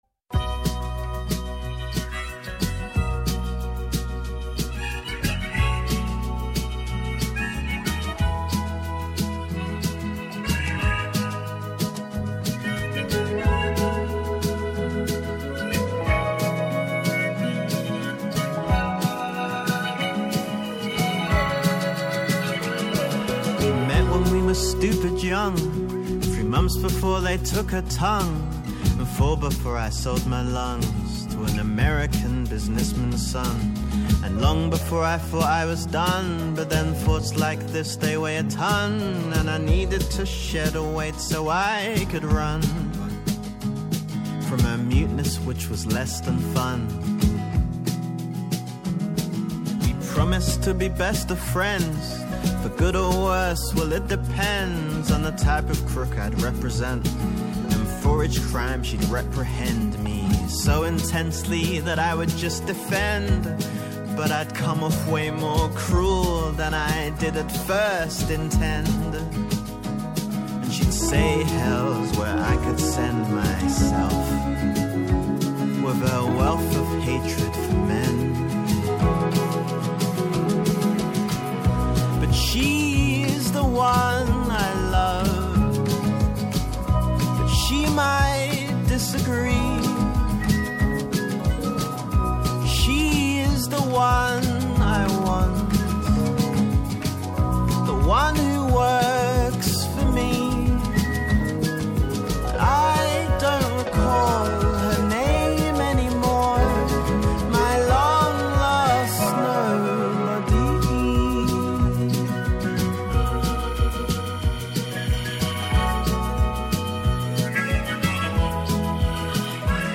Πόση επικαιρότητα μπορεί να χωρέσει σε μια ώρα; Πόσα τραγούδια μπορούν να σε κάνουν να ταξιδέψεις;